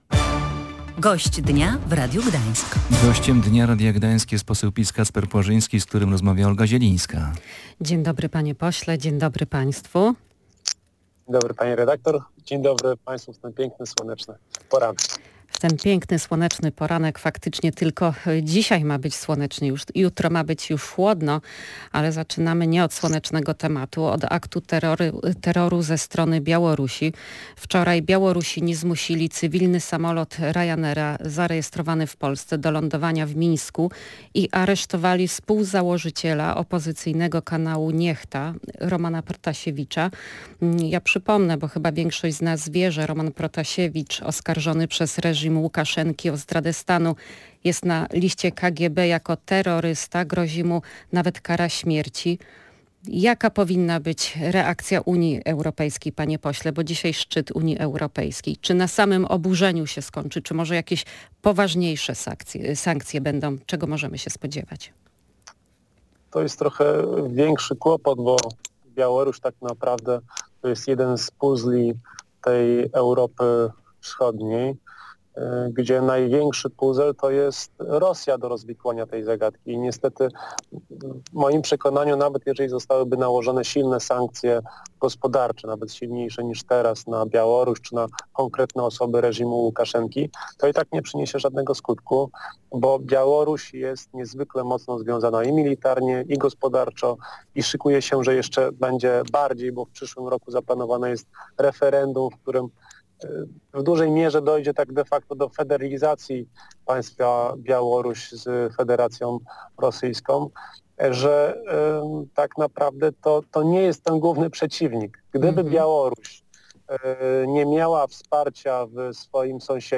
Między innymi na to pytanie odpowiedział w audycji „Gość Dnia Radia Gdańsk” poseł Prawa i Sprawiedliwości Kacper Płażyński. Poruszyliśmy również kwestię porwania samolotu cywilnego przez Białorusinów i aresztowania opozycyjnego blogera Ramana Pratasiewicza.